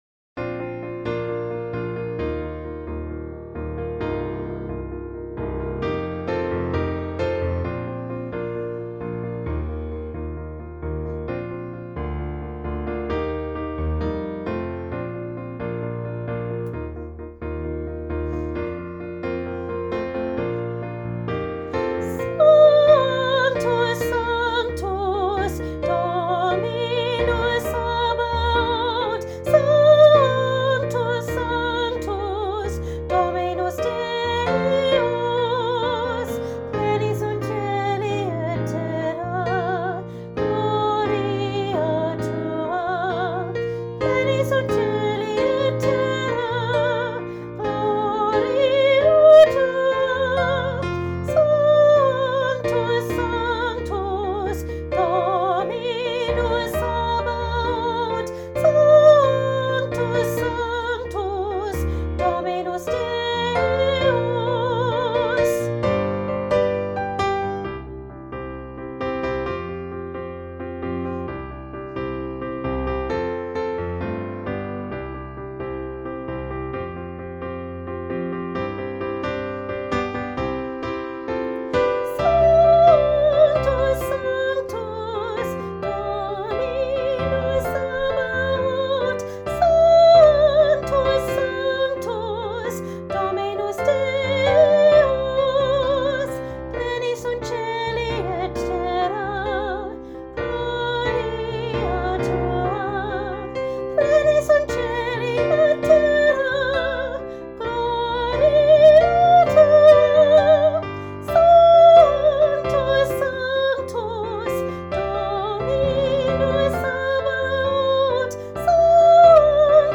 Junior Choir – A Joyful Sanctus, Part 1